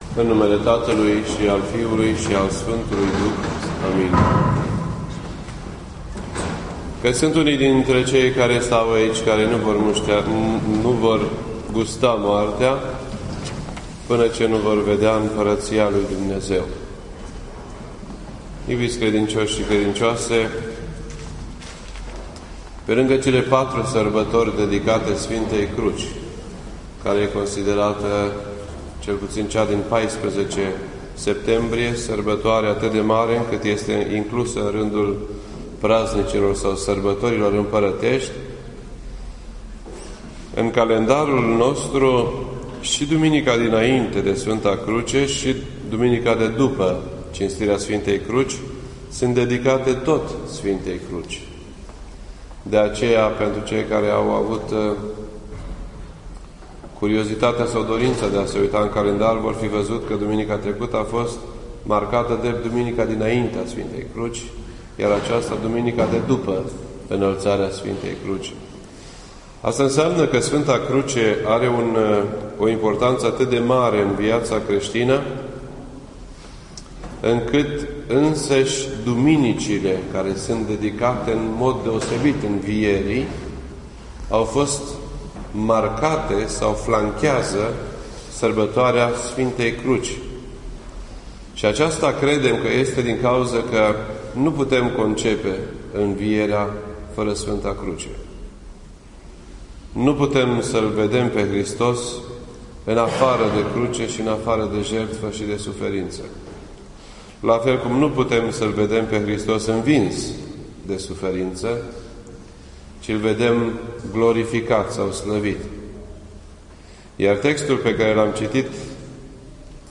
This entry was posted on Sunday, September 16th, 2012 at 7:54 PM and is filed under Predici ortodoxe in format audio.